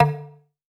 SOUTHSIDE_percussion_dub_knock.wav